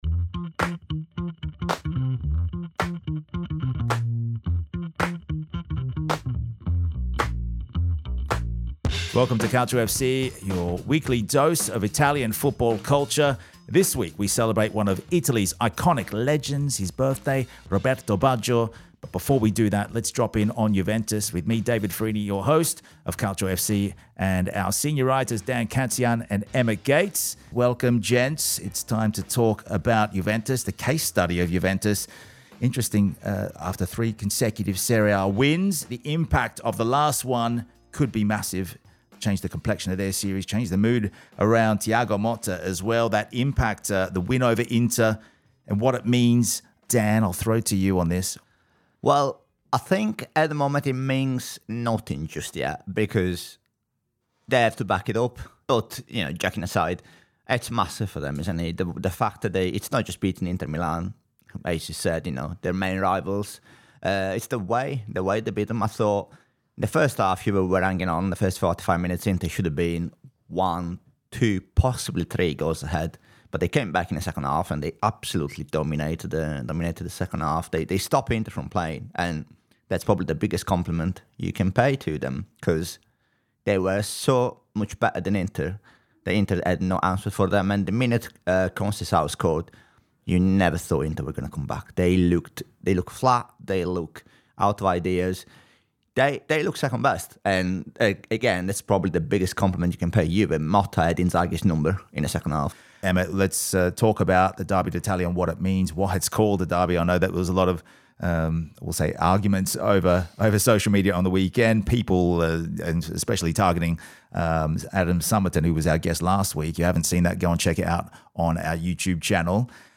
This conversation delves into the life and career of Baggio, exploring his resilience in the face of injuries, his humanizing moments